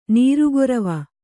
♪ nīru gorava